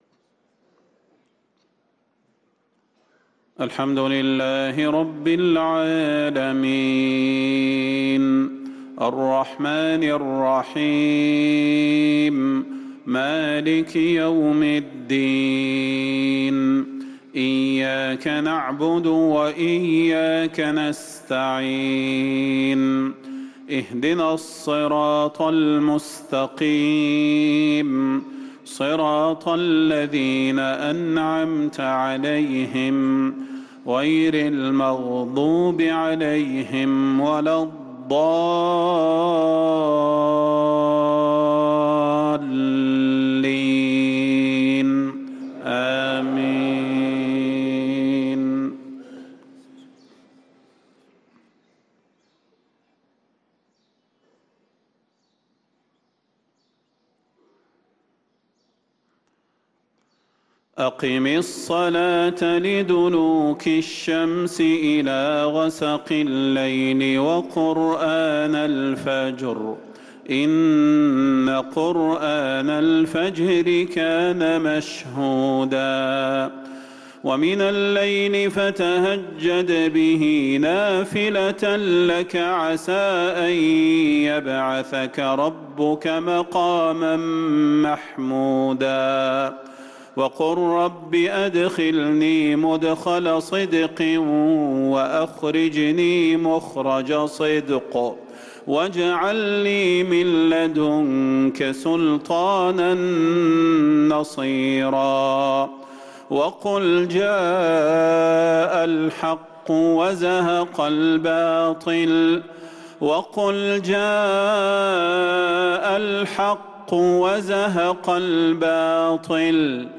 صلاة المغرب للقارئ صلاح البدير 12 رجب 1445 هـ
تِلَاوَات الْحَرَمَيْن .